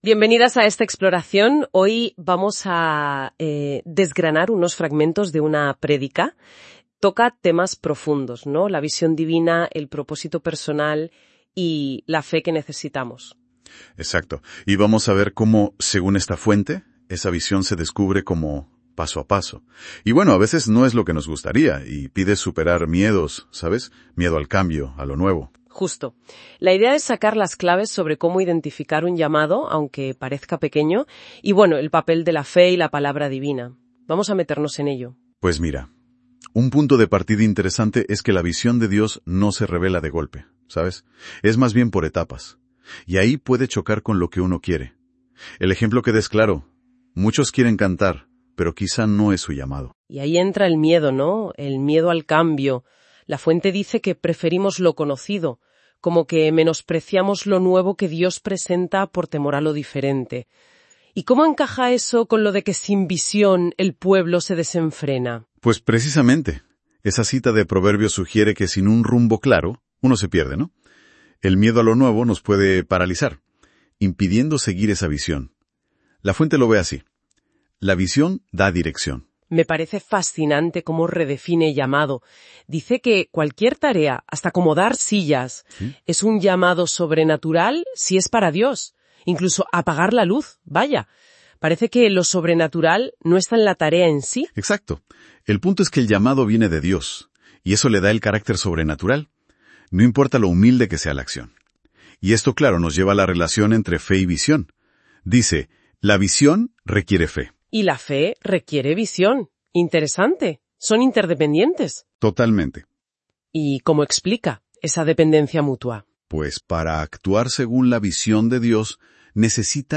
Sermons Archive - Ministros de Fuego – Iglesia Cristiana en Barcelona, Anzoátegui